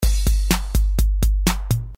描述：用ReBirth中的Regulator Mod制作的声音。
Tag: 节拍 重生 调节器 TB-303